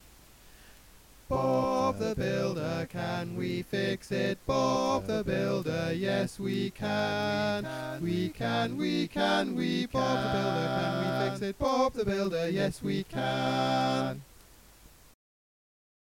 Key written in: C Major
How many parts: 4
Type: Barbershop
All Parts mix: